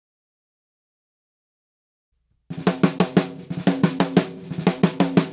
Drumset Fill 20.wav